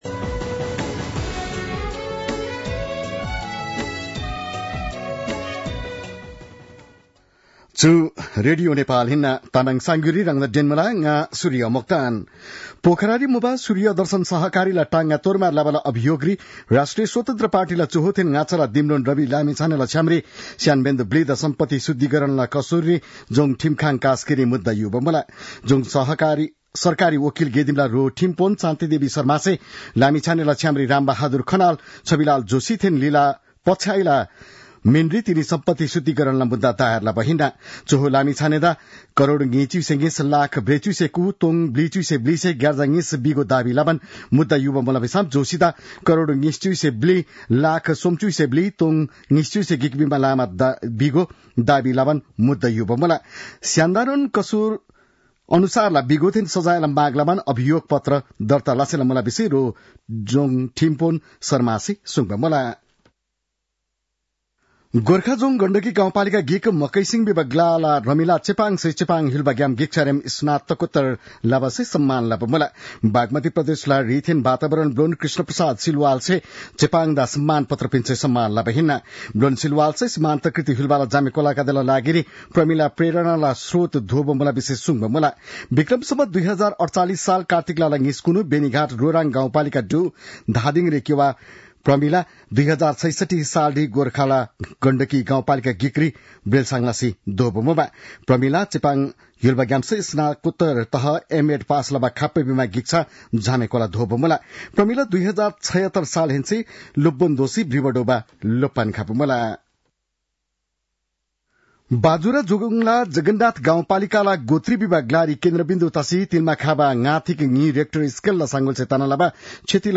तामाङ भाषाको समाचार : ८ पुष , २०८१